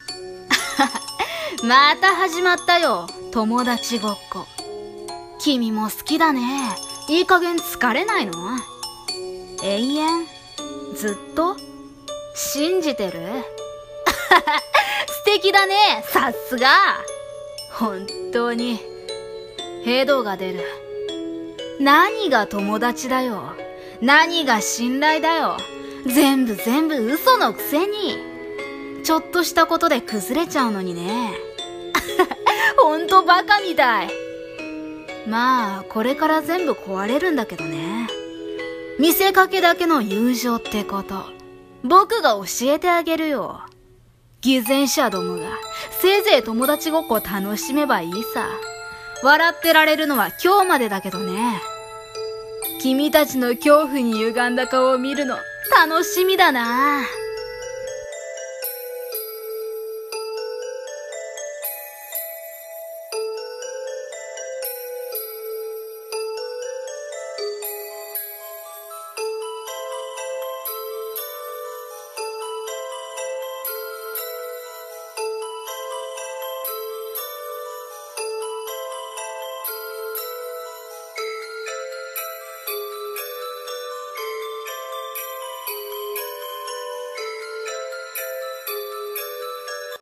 声劇】友達ごっこ。